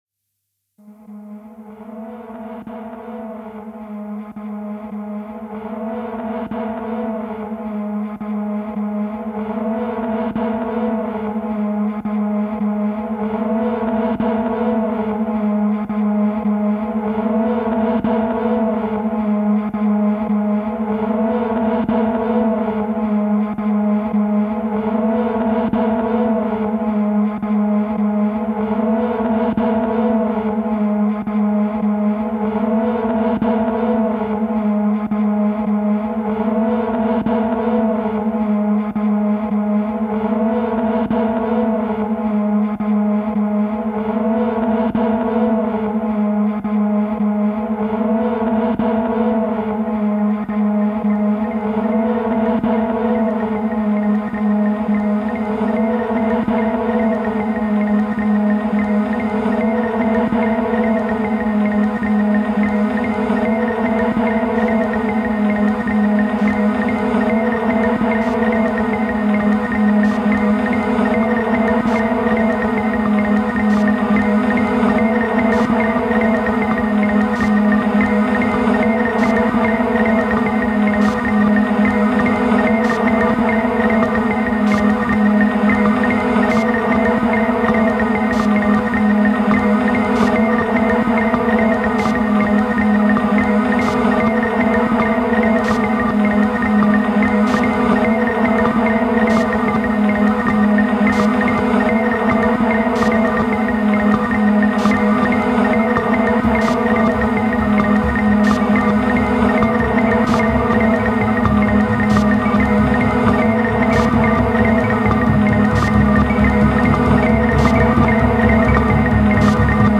mastering his acoustic drumset